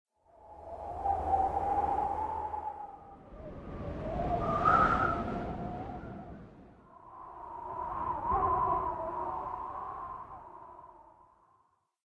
CHQ_FACT_whistling_wind.ogg